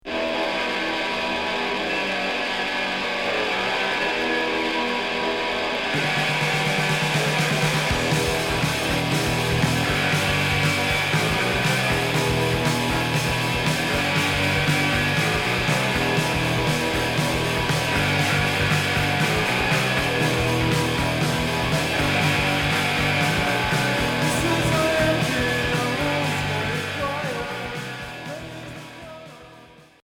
Rock garage punk Deuxième 45t retour à l'accueil